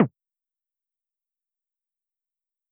tetromino_drop.wav